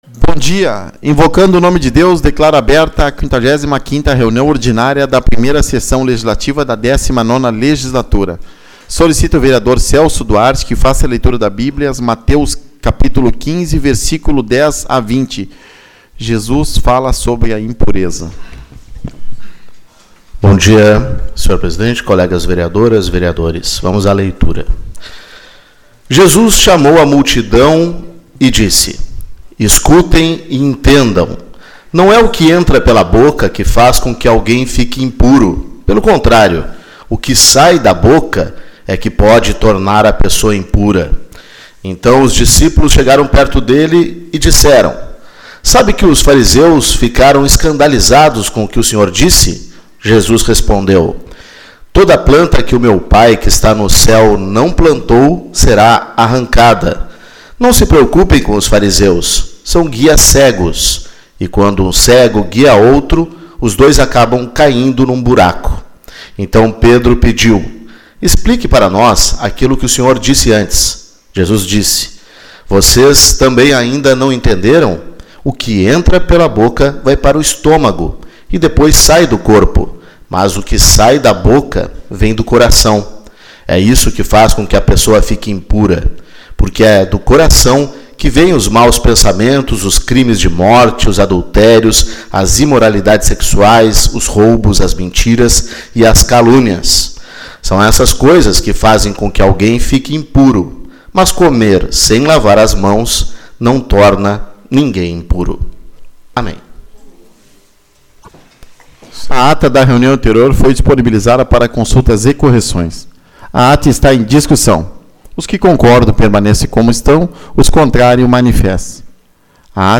04/09 - Reunião Ordinária